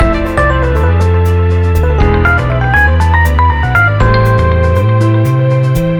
こちらはよくあるジャジーな進行で、2つめのコードがIII7ですね。
オルタード・ドミナント・スケール
5th9thの音を上下に変位させて、難しめのサウンドを交えるスケールでした。